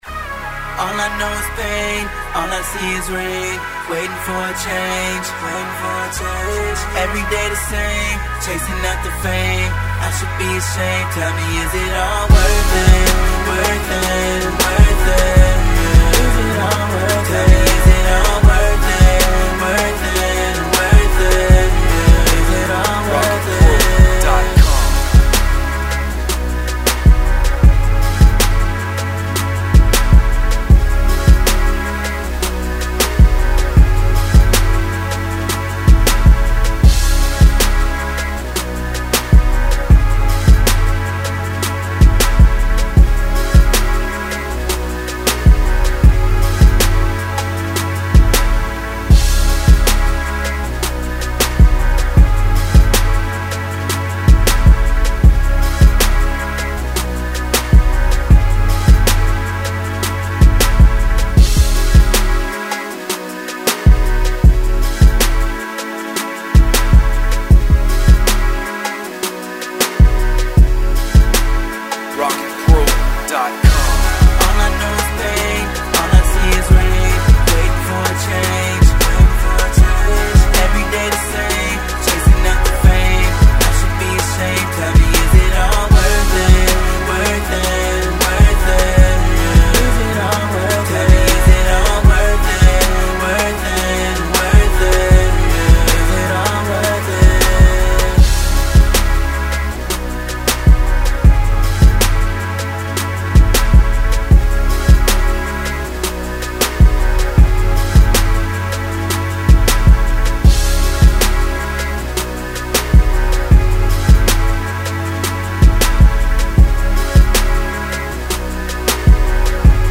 rap beat with catchy synth melodies
156.5 BPM. Chill Urban/Rap beat with catchy synth melodies.